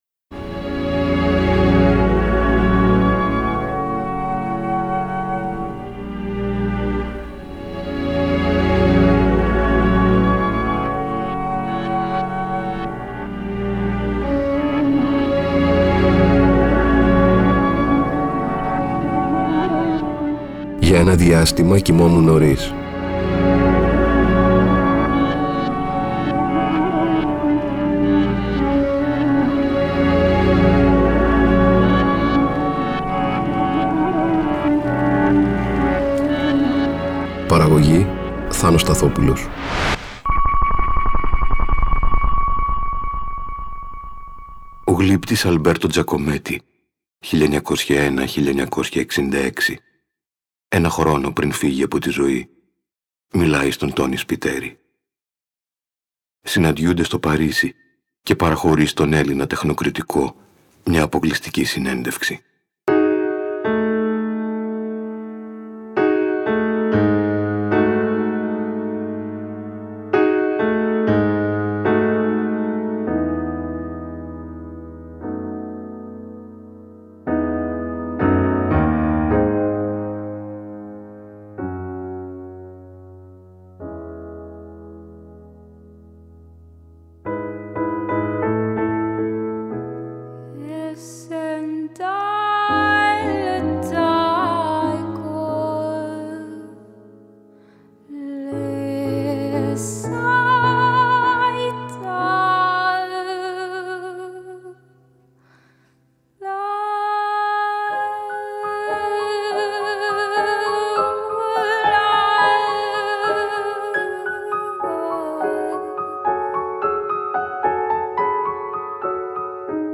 Μια συνέντευξη